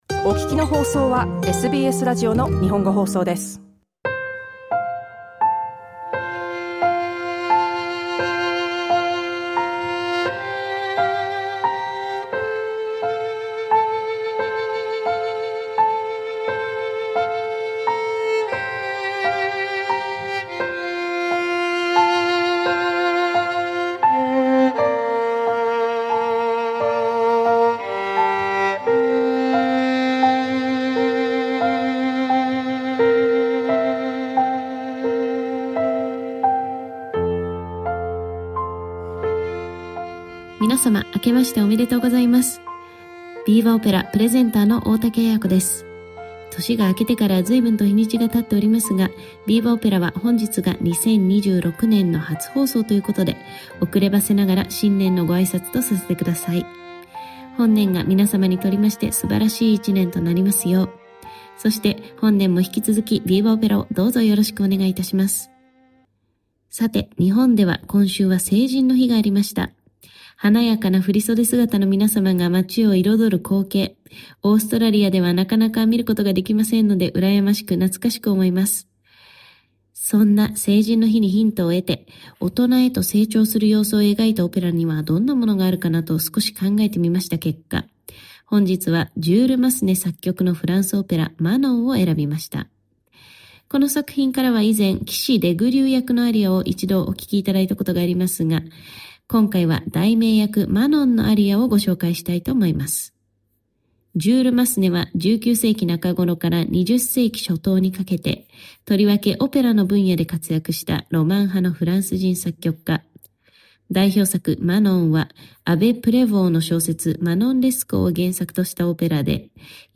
Opera 85) SBS Japanese 05:56 Japanese ラジオ番組内で解説に続いて紹介するのは、フランスのソプラノ歌手パトリシア・プティボンによる歌唱です。